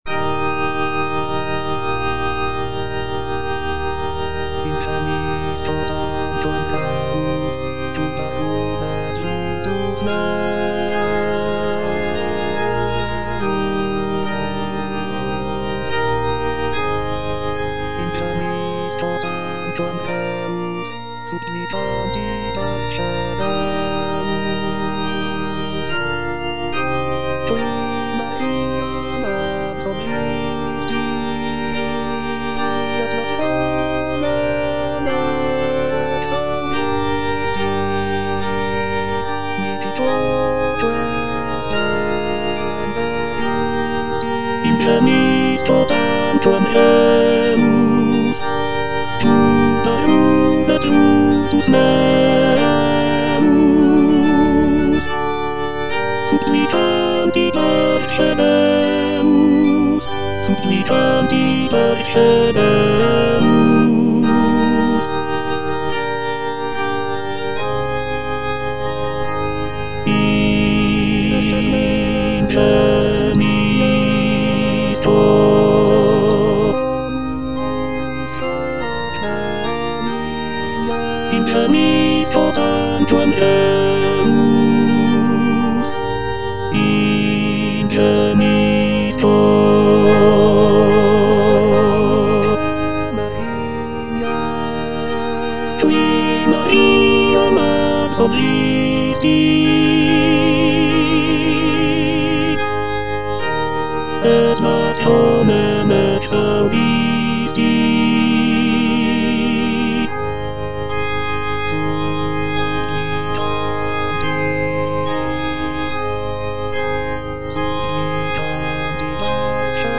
Tenor (Emphasised voice and other voices) Ads stop
is a sacred choral work rooted in his Christian faith.